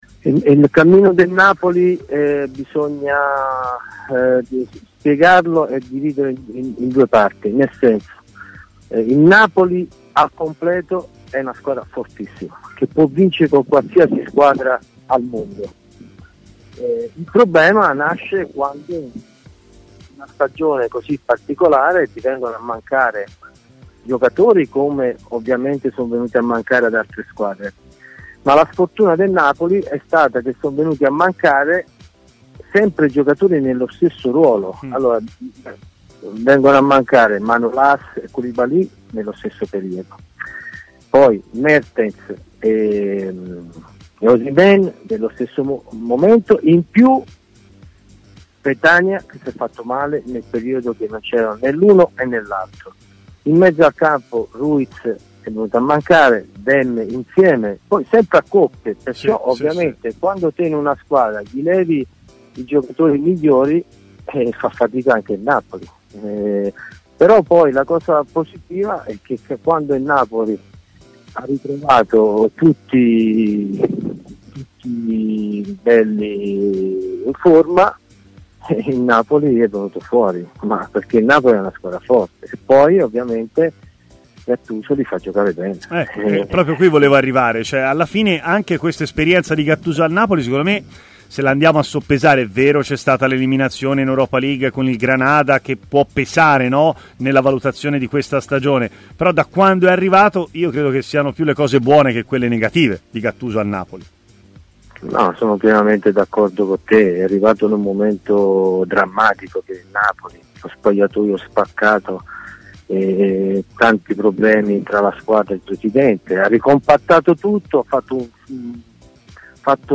L'ex attaccante Francesco Baiano ha parlato a Stadio Aperto, trasmissione di TMW Radio